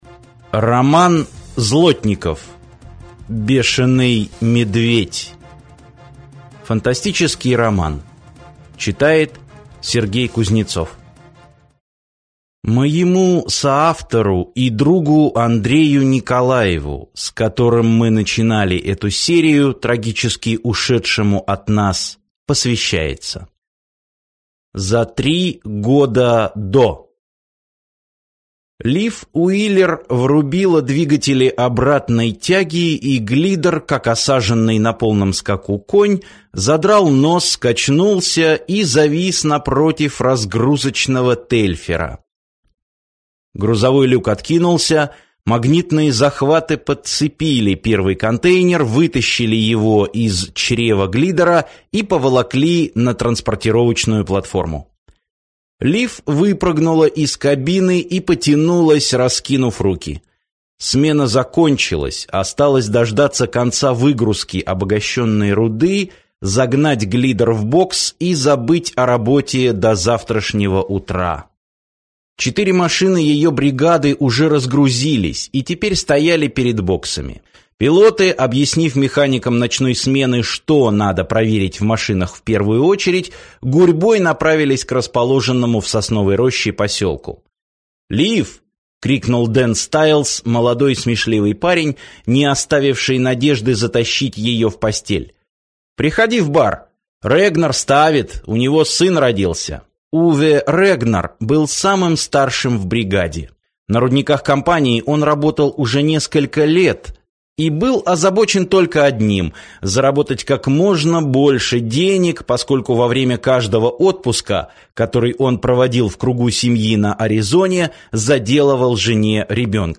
Аудиокнига Бешеный медведь - купить, скачать и слушать онлайн | КнигоПоиск